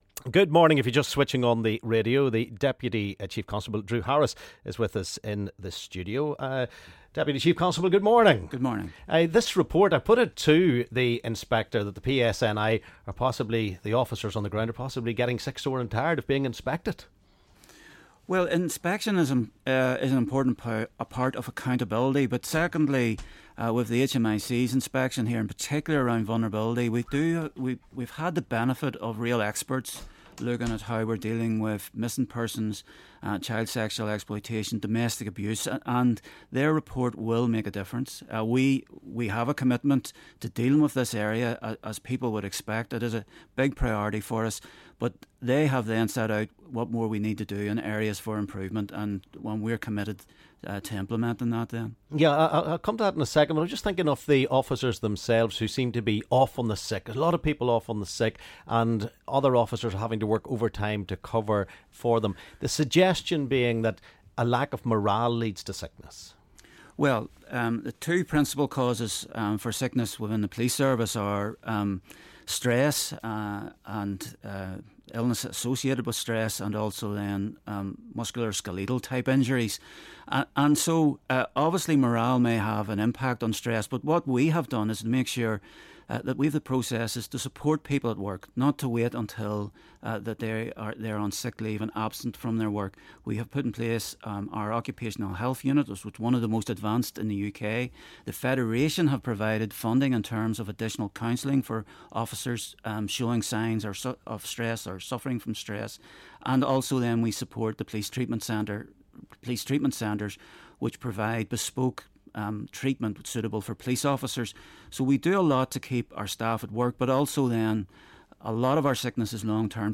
LISTEN: Dep Chief Con Drew Harris joins us in the studio after a major inspection report.